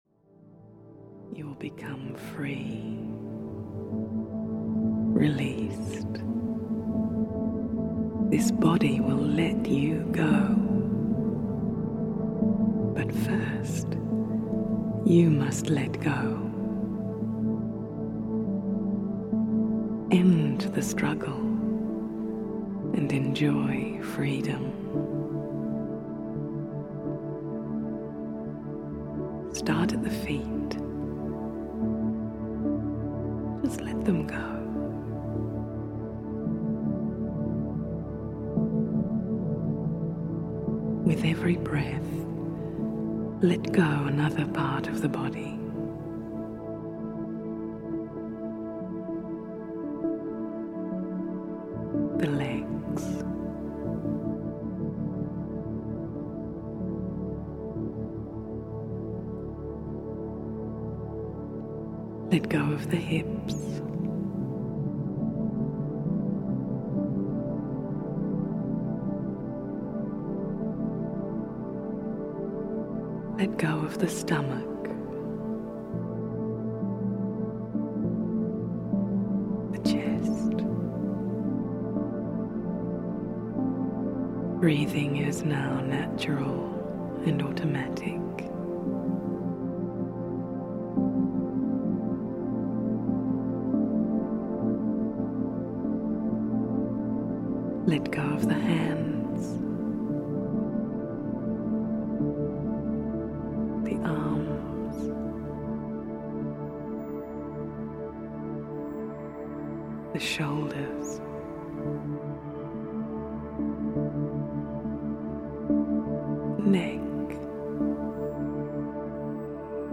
Ukázka z knihy
You become free."Peace of Mind" by Brahma Khumaris offers its listeners a guided, musical meditation to help them unwind, relax, and let go of their stress.